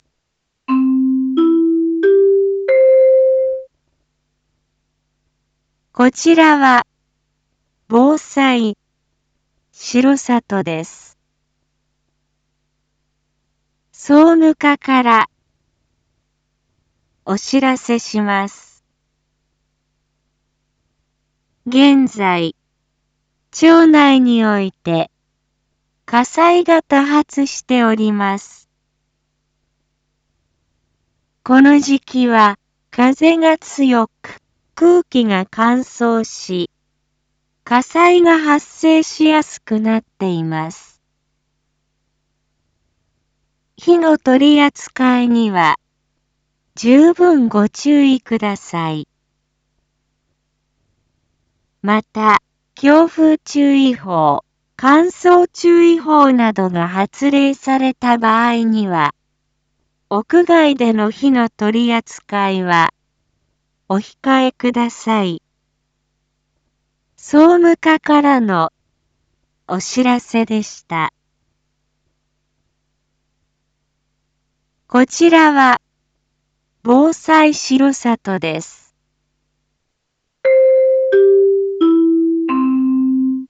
Back Home 一般放送情報 音声放送 再生 一般放送情報 登録日時：2025-03-14 19:01:25 タイトル：火災注意喚起③ インフォメーション：こちらは防災しろさとです。